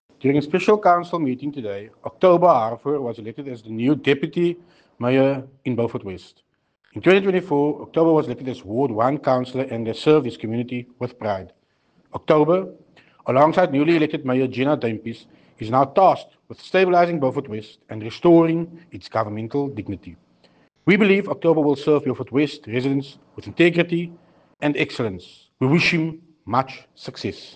soundbite by Tertuis Simmers